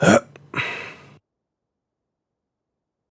Grunt1.ogg